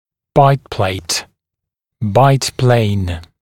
[baɪt pleɪt] [baɪt-pleɪn][байт плэйт] [байт-плэйн]накусочная площадка